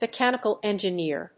アクセントmechánical enginéer